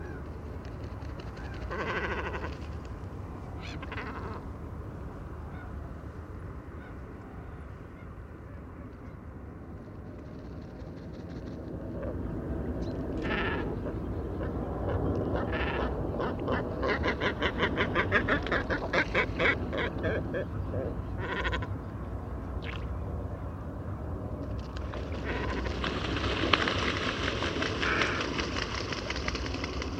Grand cormoran - Mes zoazos
grand-cormoran.mp3